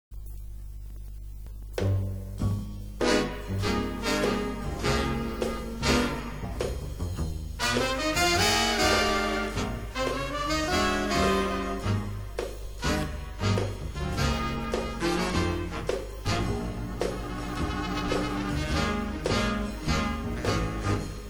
Remove hiss and background noise from your old tapes and records.
raw sample
de clicked   de noised